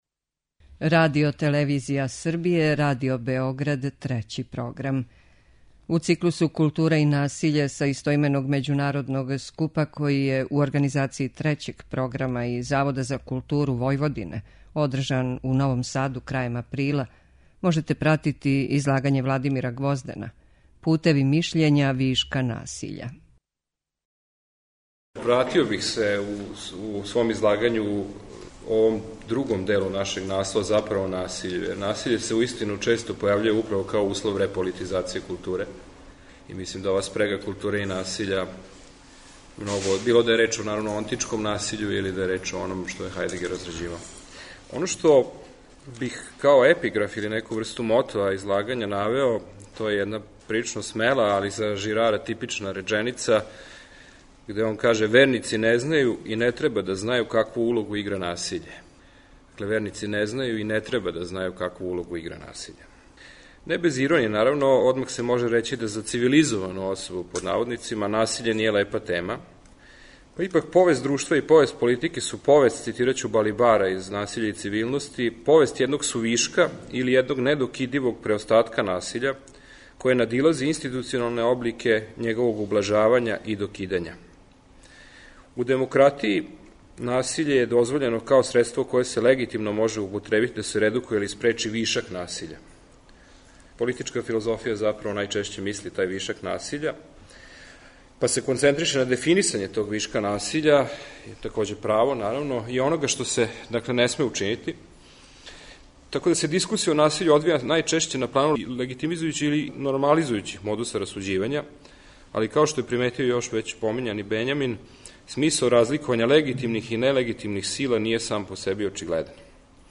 У циклусу КУЛТУРА И НАСИЉЕ, који емитујемо средом, са истоименог научног скупа који су, у Новом Саду крајем априла, организовали Трећи програм и Завод за културу Војводине, емитујемо прилоге са овога скупа и разговоре о излагањима.